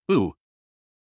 母音/ʊ/は、日本語の「ウ」に近い音でですが、発音の書籍などでは「ウ」と「オ」の中間の音と紹介されることが多いです。低くて深いような音です。
母音/ʊ/のみの発音
・短母音
母音Shortʊのみの発音.mp3